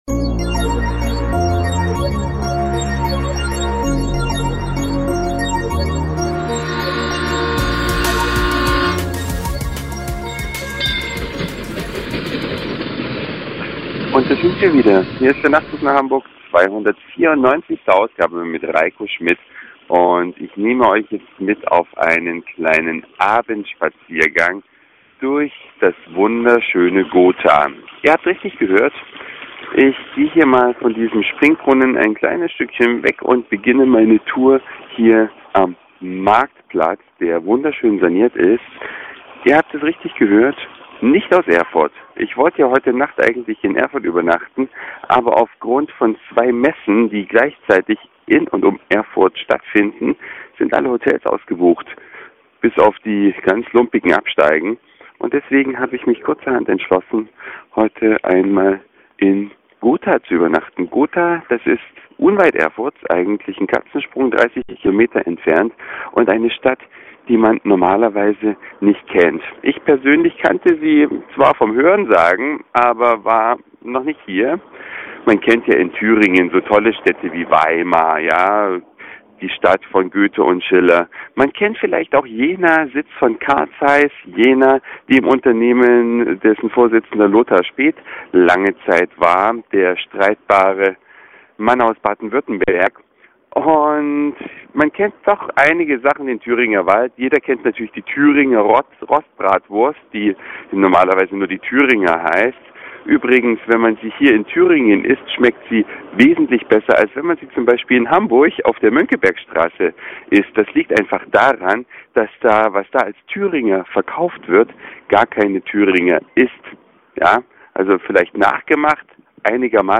NnH294 Abendspaziergang durch Gotha
Eine Reise durch die Vielfalt aus Satire, Informationen, Soundseeing und Audioblog.